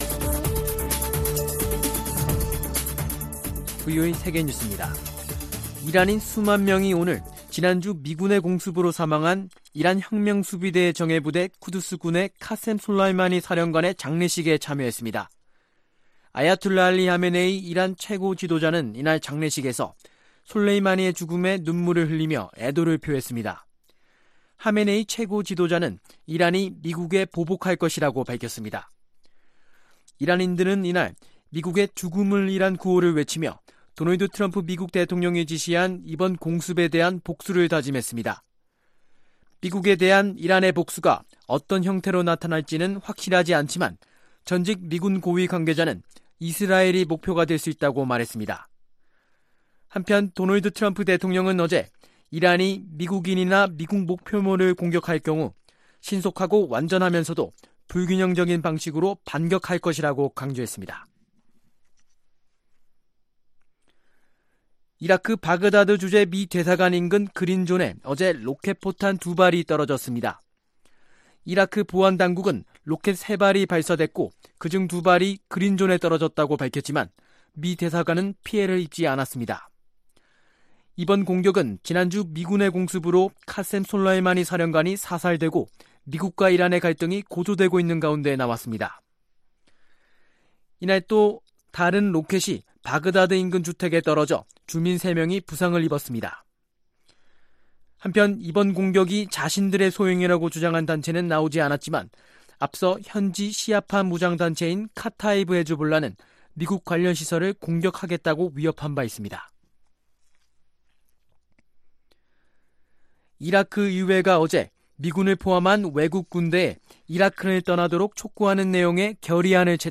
VOA 한국어 간판 뉴스 프로그램 '뉴스 투데이', 2019년 1월 6일 2부 방송입니다. 도널드 트럼프 미국 대통령은 김정은 북한 국무위원장이 자신에게 한 약속을 깰 것으로 생각하지 않지만, 그럴 수도 있다고 말했습니다. 미 국무부가 2일 발표한 ‘2019 세계 군비 지출과 무기 수출입’ 보고서에 따르면 북한은 국내총생산 GDP 대비 군비 지출과 인구 대비 군인 비율이 세계에서 가장 높았습니다.